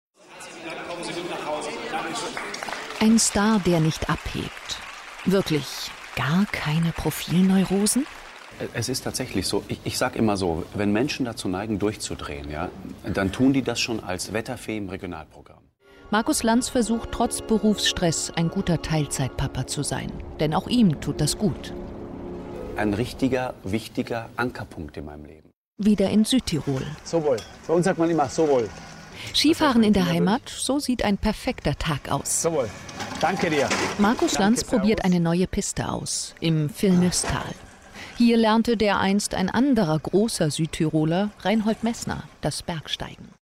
Mittel plus (35-65)
Comment (Kommentar)